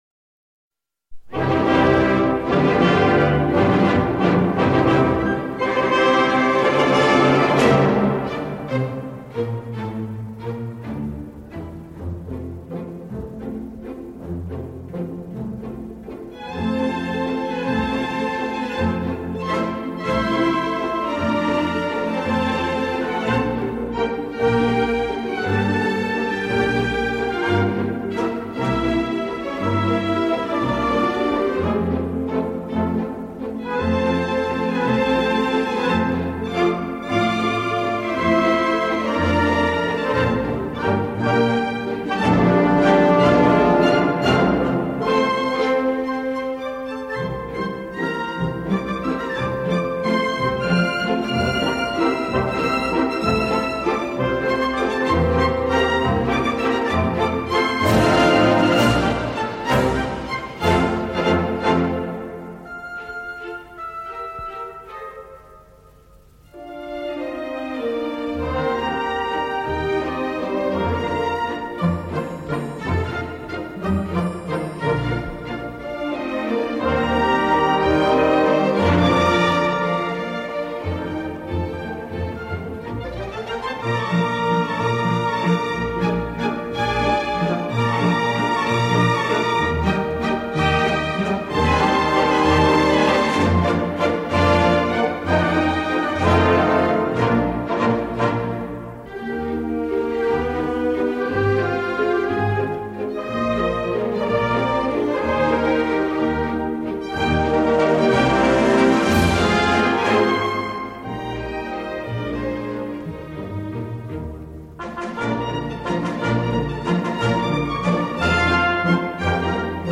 维也纳森林的故事（纯音乐）--未知